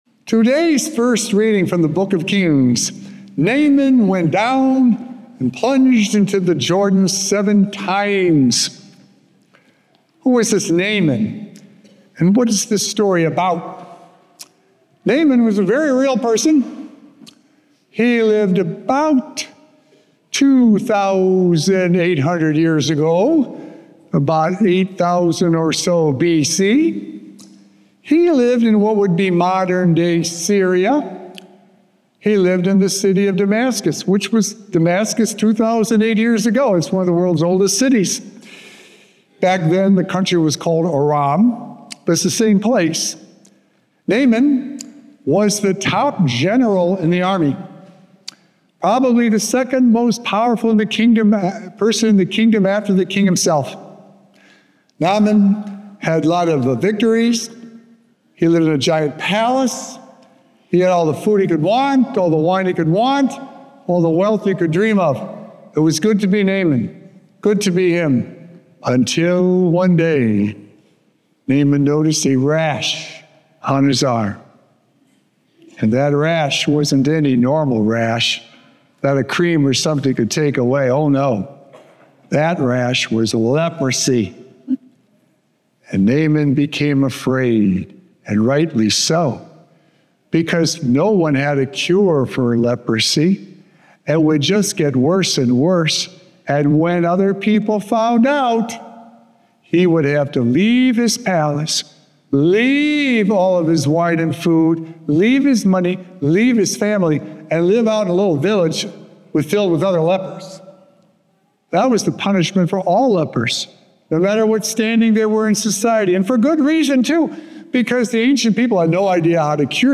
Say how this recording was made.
No matter what we do and how high up we hold ourselves, there is nothing more humbling than being cleansed by Christ. This connection with God strengthens us and allows us to be accepted and loved wherever we may be. Recorded Live on Sunday, October 12th, 2025 at St. Malachy Catholic Church.